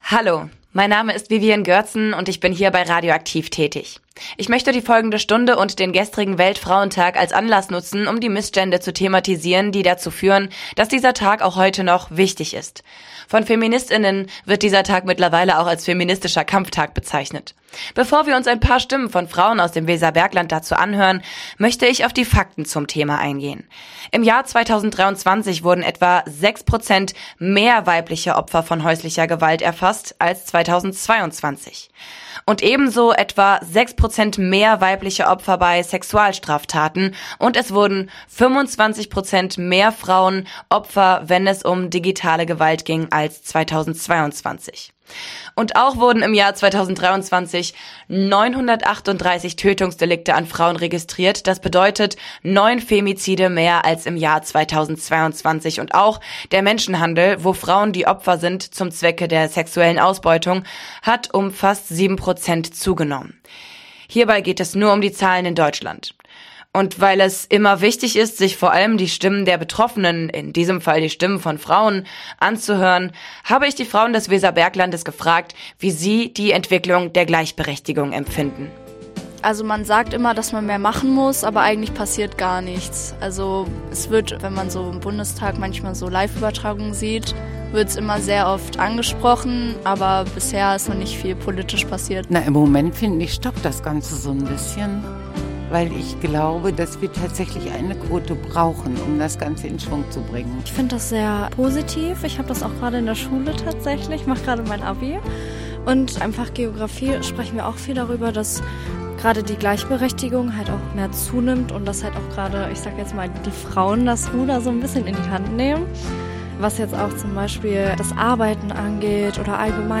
die weiblichen Stimmen des Weserberglandes zur Gleichberechtigung befragt